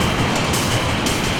RI_DelayStack_170-02.wav